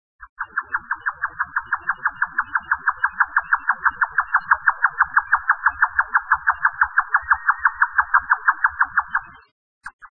2126e「鳥の鳴声」
〔ヨタカ〕キョキョキョキョ／草原や明るい林などに棲息，普通・夏鳥，29p，雌雄ほ
yotaka.mp3